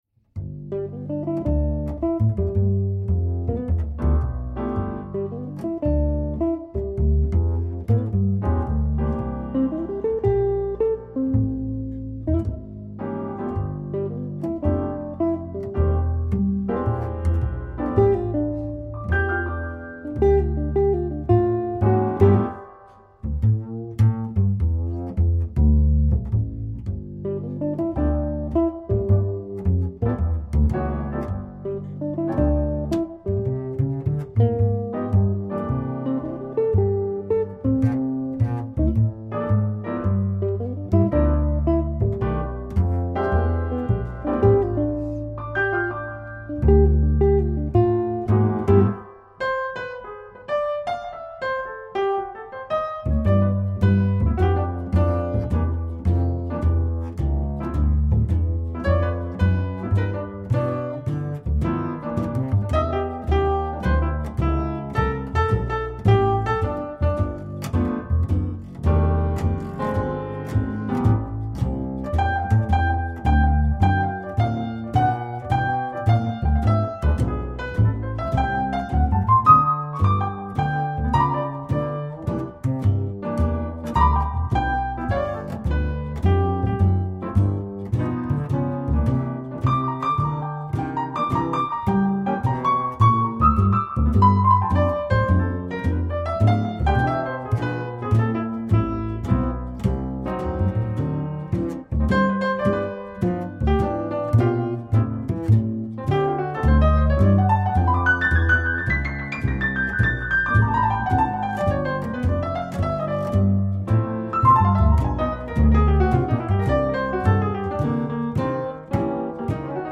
Jazz Duo / Jazz Trio and Big Band with Vocals